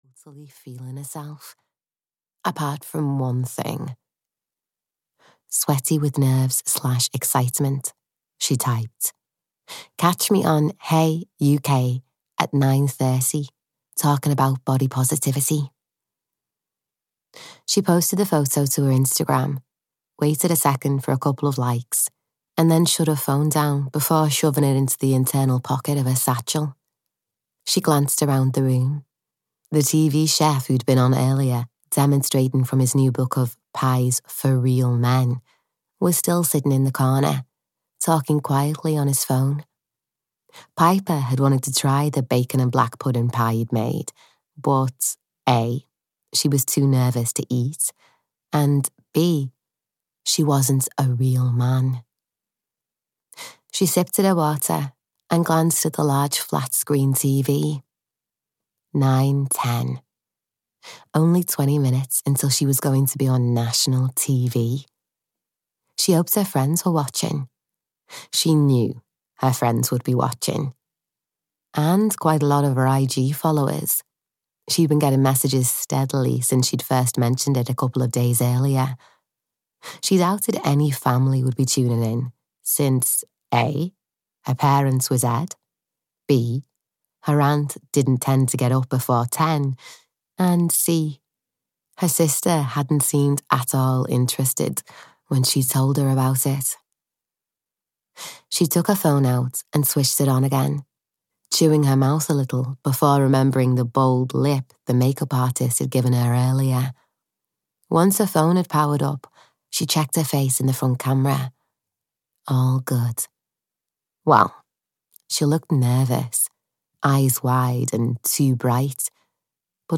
The One That Got Away (EN) audiokniha
Ukázka z knihy